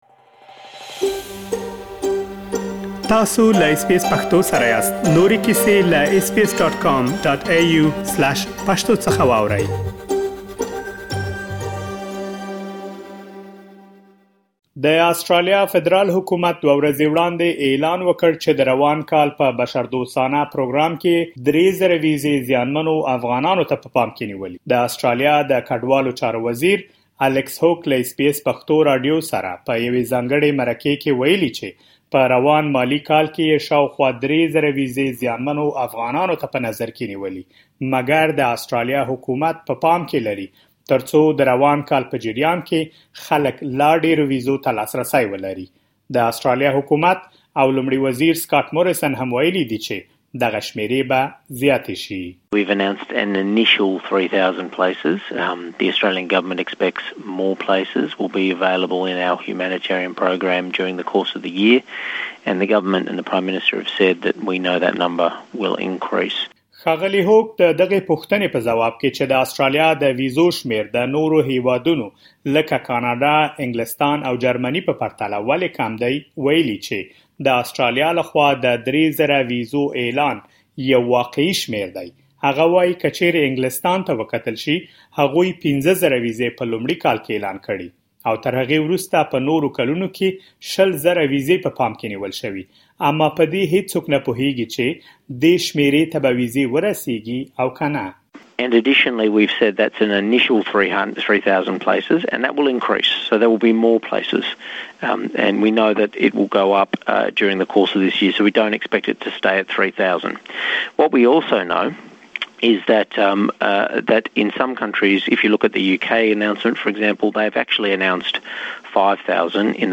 د اسټراليا حکومت لخوا افغانانو ته د بشري ويزو د ورکړې په اړه د مهاجرت له وزير سره ځانګړې مرکه
د آسترالیا د کډوالو چارو وزیر الیکس هوک له اس بي اس پښتو راډیو سره په یوې ځانګړې مرکې کې و يلي چې په روان مالي کال کې یې شاوخوا ۳۰۰۰ وېزې زیانمو افغانانو ته په نظر کې نیولي، مګر په پام کې ده ترڅو په روان کال کې د ویز و شمېر تر دې هم زیات شي.